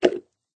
plop_hard.ogg